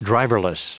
Prononciation du mot driverless en anglais (fichier audio)
Prononciation du mot : driverless